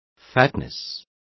Complete with pronunciation of the translation of fatness.